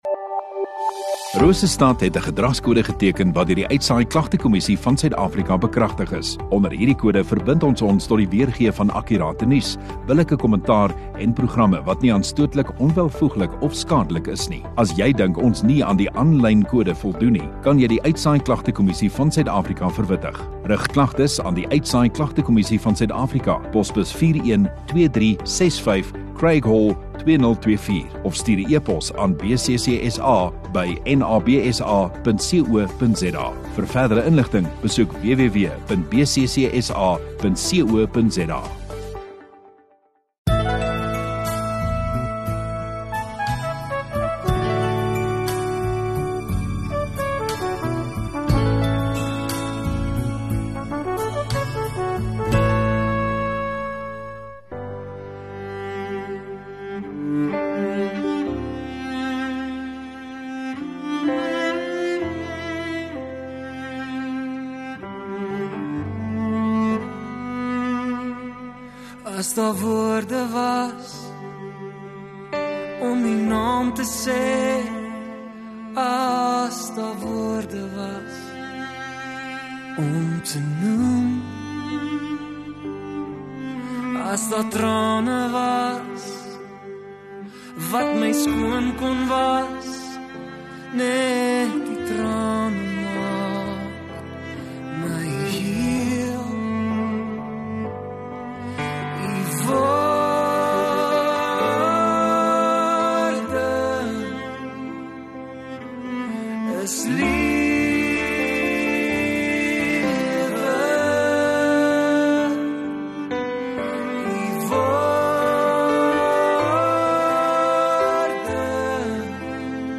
16 Jun Sondagaand Erediens